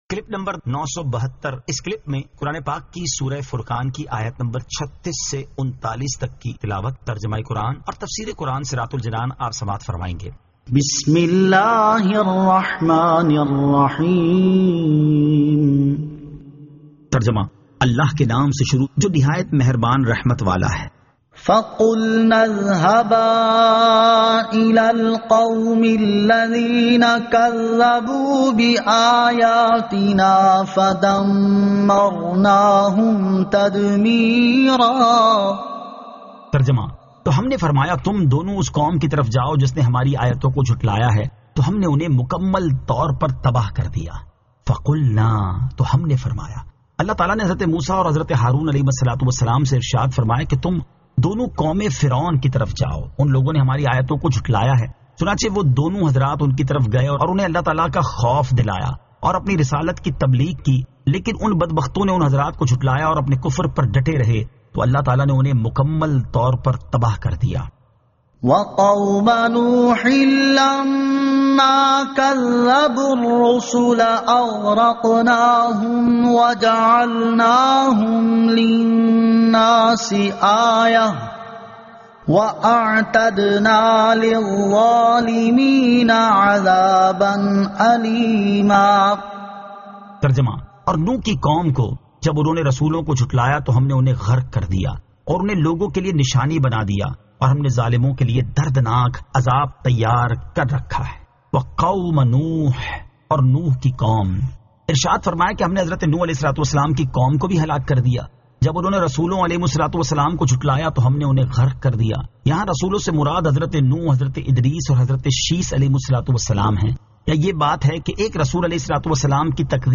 Surah Al-Furqan 36 To 39 Tilawat , Tarjama , Tafseer